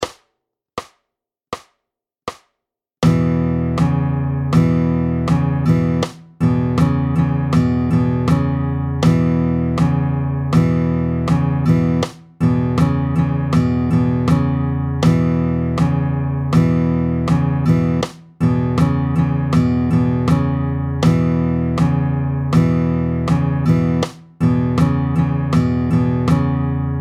32-12 Le honky tonk 2, variante binaire tempo 80
Voici une variante binaire sur deux mesures, plus lourde car les noires sont plus représentées et avec une syncope entre la première et la seconde mesure.